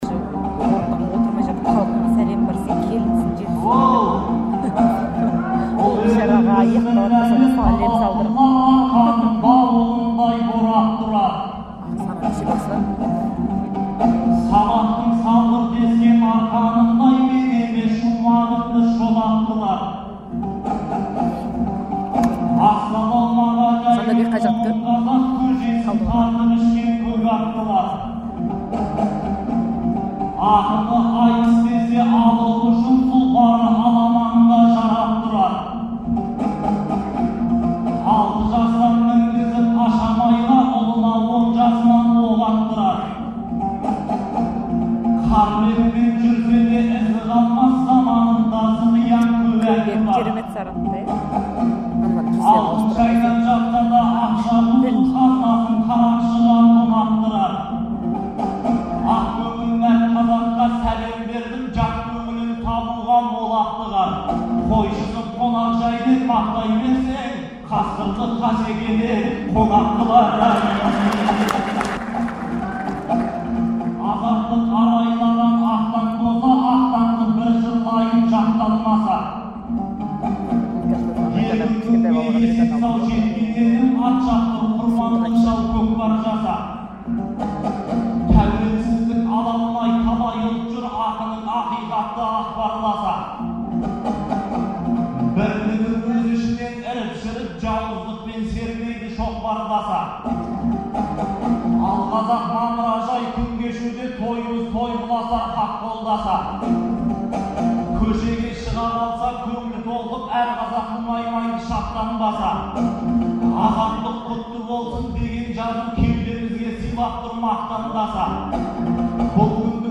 Астанада өткен айтыста